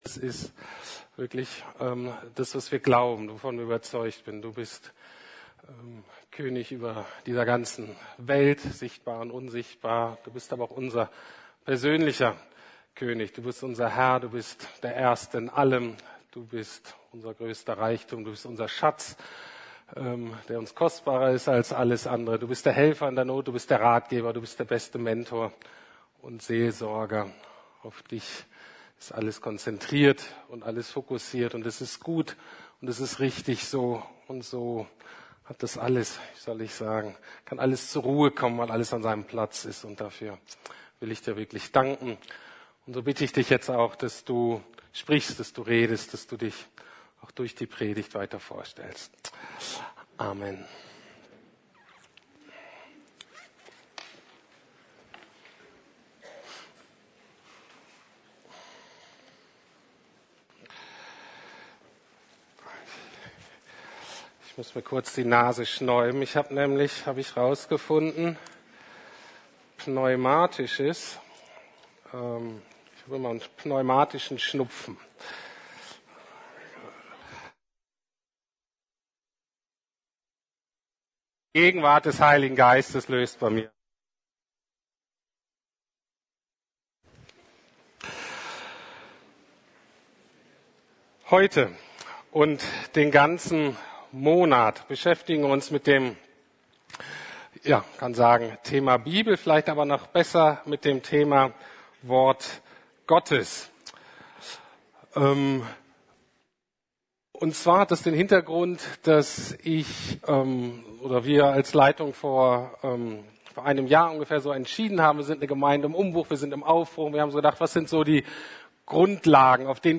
Bibel und ihre historische Zuverlässigkeit: kann ich ihr trauen? ~ Predigten der LUKAS GEMEINDE Podcast